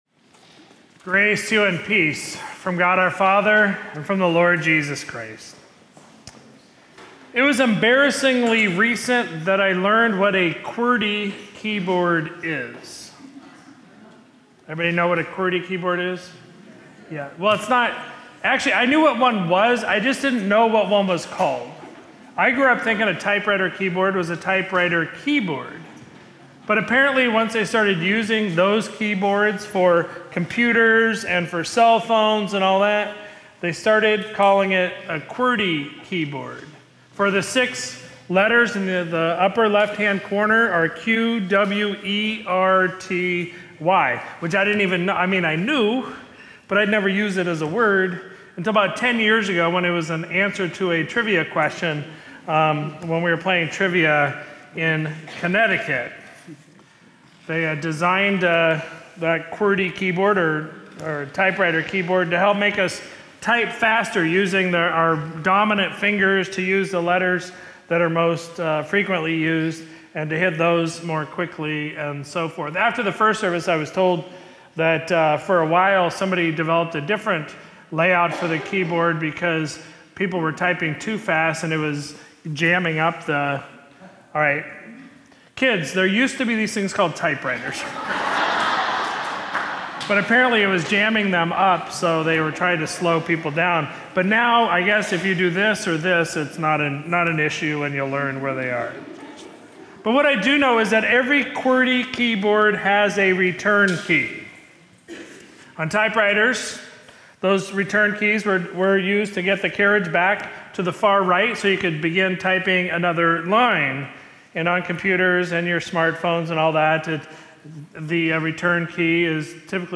Sermon from Sunday, December 8, 2024